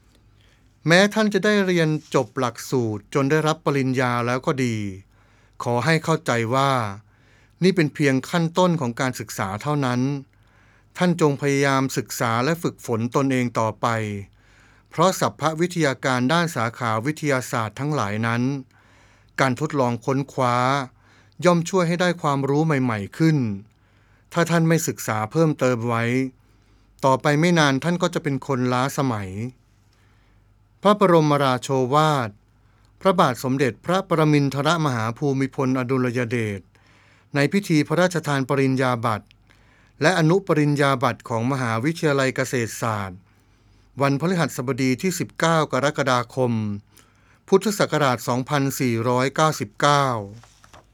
พระบรมราโชวาท
ในพิธีประสาทปริญญาบัตรและอนุปริญญาบัตร ของมหาวิทยาลัยเกษตรศาสตร์